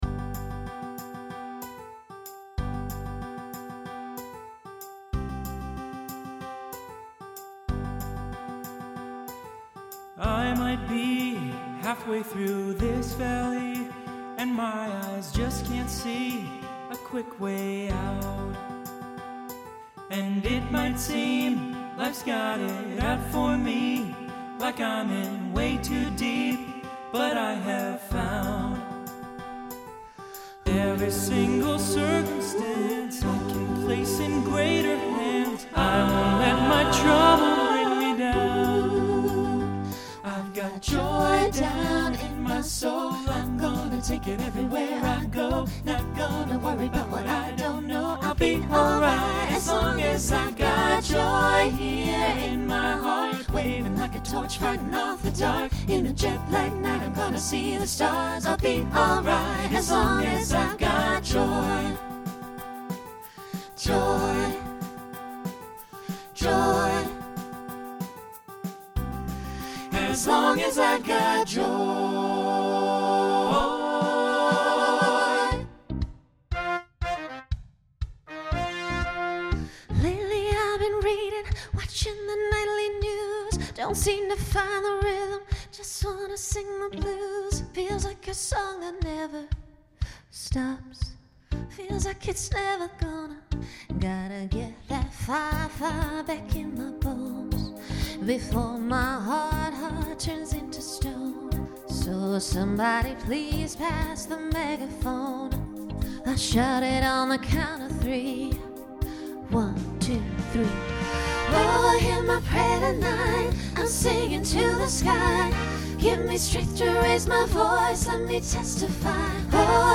(small group)
(SSA then SATB)
Genre Folk , Pop/Dance Instrumental combo
Transition Voicing Mixed